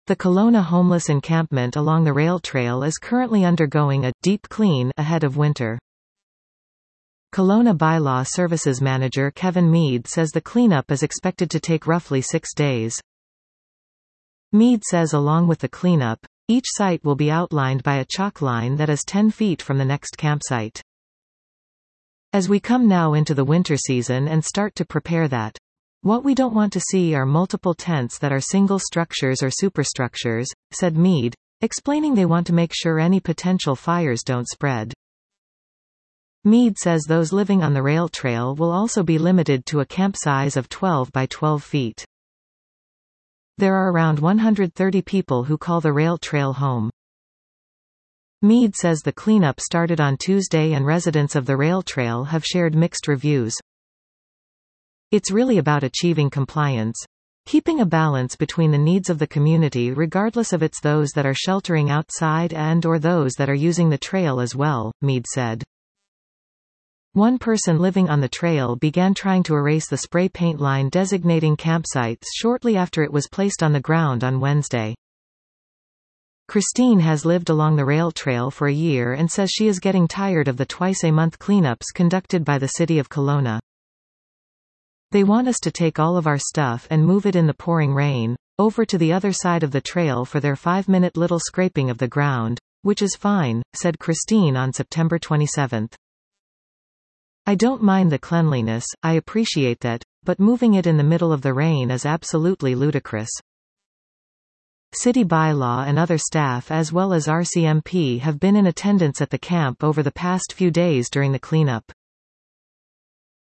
Castanet Listen to this article 00:01:53 The Kelowna homeless encampment along the rail trail is currently undergoing a "deep clean" ahead of winter.